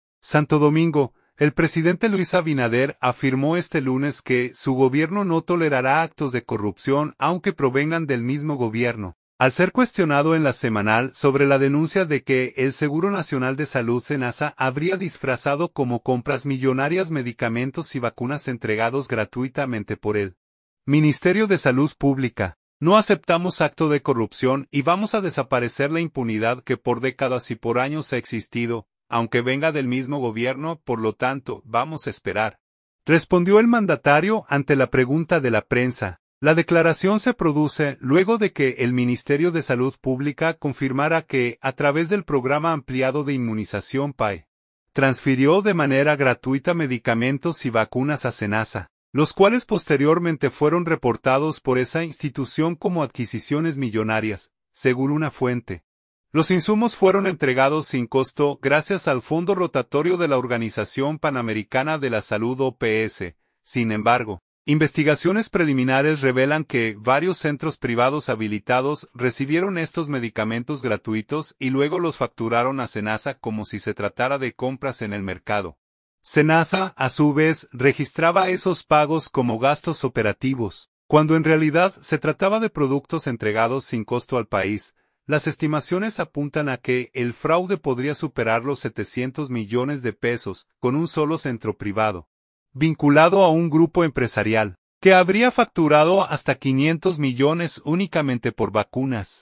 Santo Domingo. – El presidente Luis Abinader afirmó este lunes que su gobierno no tolerará actos de corrupción “aunque provengan del mismo gobierno”, al ser cuestionado en La Semanal sobre la denuncia de que el Seguro Nacional de Salud (SENASA) habría disfrazado como compras millonarias medicamentos y vacunas entregados gratuitamente por el Ministerio de Salud Pública.
“No aceptamos acto de corrupción y vamos a desaparecer la impunidad que por décadas y por años ha existido, aunque venga del mismo gobierno, por lo tanto, vamos a esperar”, respondió el mandatario ante la pregunta de la prensa.